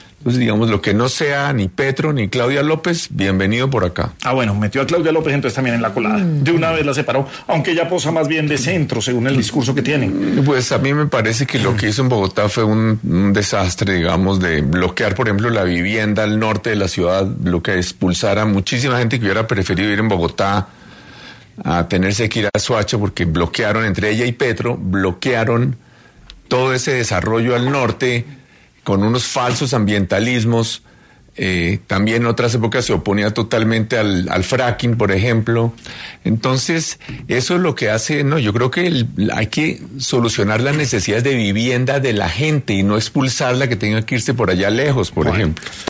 Enrique Peñalosa estuvo en Sin Anestesia de La Luciérnaga y habló sobre los proyectos de vivienda al norte de Bogotá que fueron bloqueados por Claudia López y Gustavo Petro